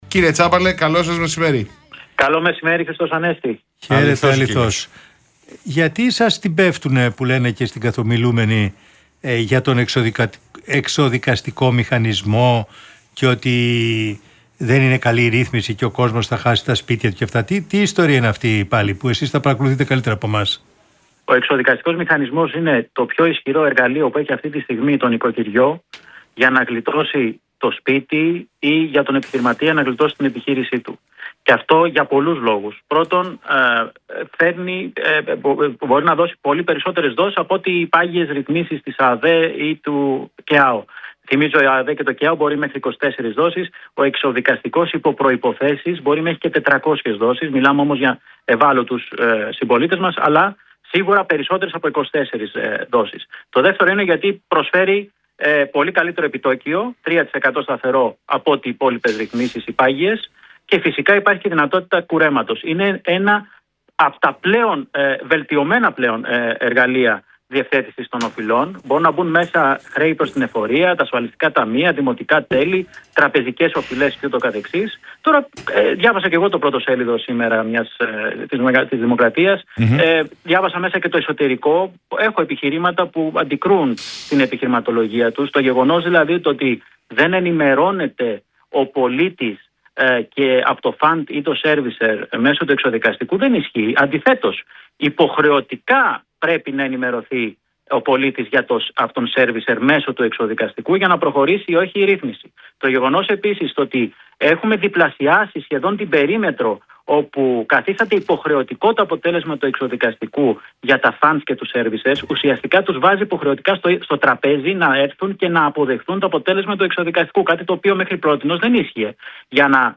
Τα παραπάνω επιβεβαίωσε ο κυβερνητικός εκπρόσωπος Παύλος Μαρινάκης, ο οποίος, μιλώντας στα Παραπολιτικά, εξέφρασε την αισιοδοξία του για το ύψος του πλεονάσματος και προανήγγειλε ανακοινώσεις για τα νέα μέτρα.